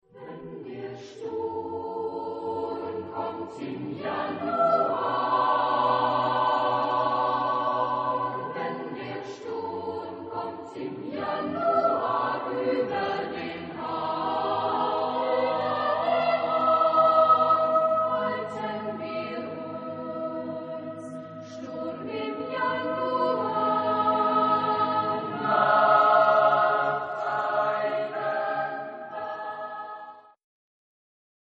Genre-Style-Forme : Cycle ; Pièce chorale ; Profane
Type de choeur : SSAATBB  (7 voix mixtes )
Tonalité : atonal